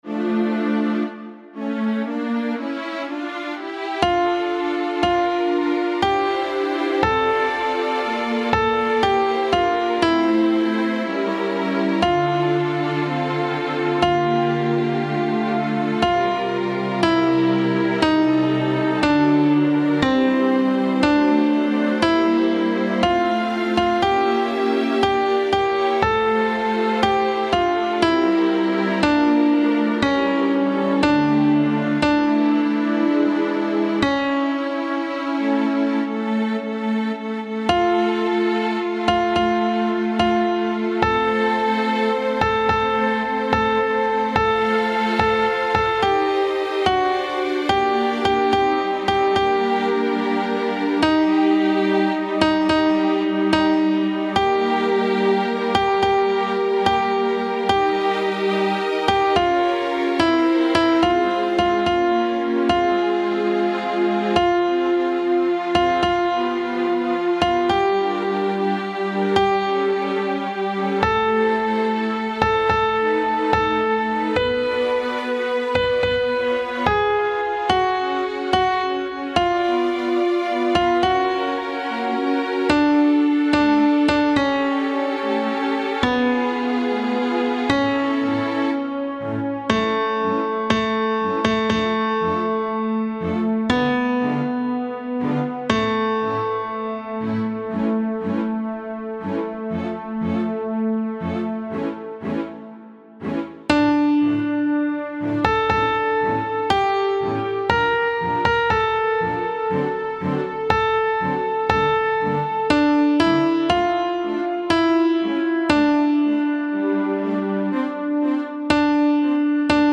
Contralto
Mp3 Música
6.-Libera-me-CONTRALTO-MUSICA.mp3